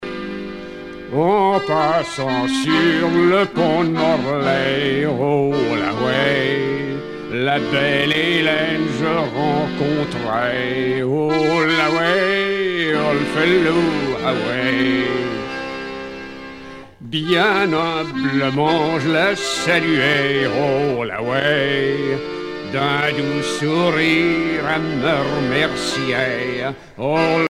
laisse
Pièce musicale éditée